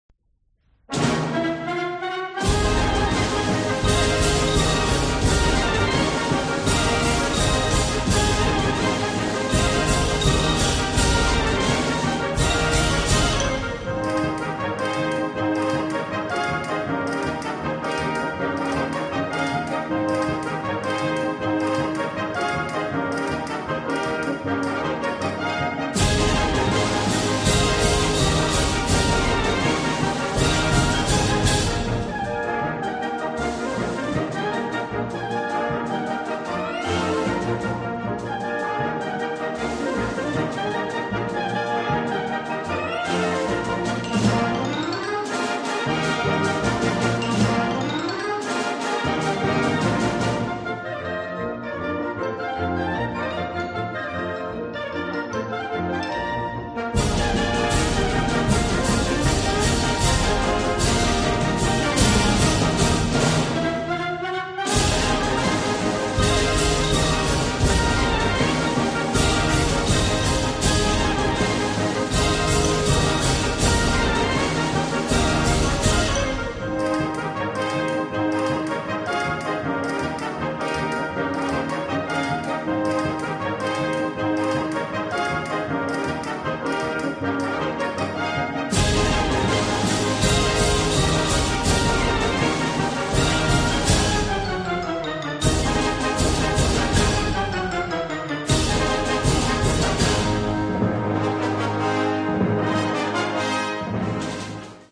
Noten für Blasorchester.
• View File Blasorchester